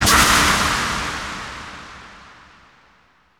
Index of /90_sSampleCDs/Houseworx/12 Vocals